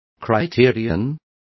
Complete with pronunciation of the translation of criterion.